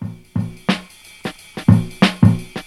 Track 49 (SV Tour) Drums only.wav